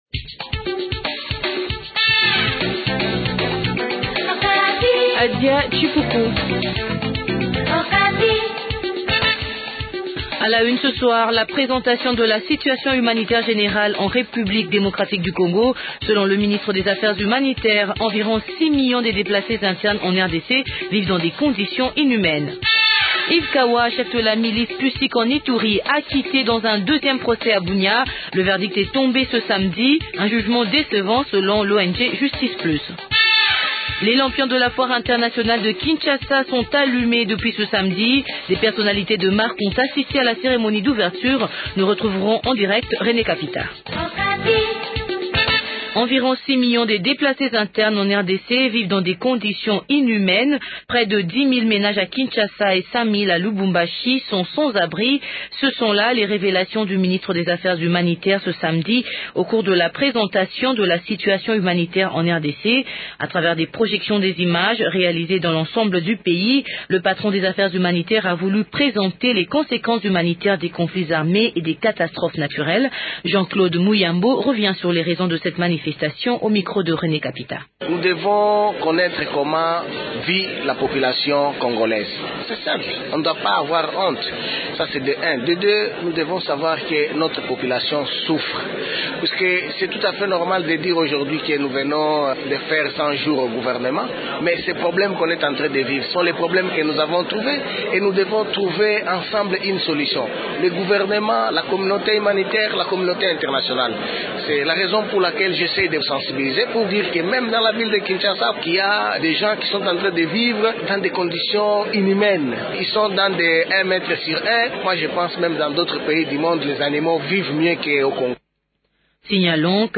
Journal Français Soir 18h00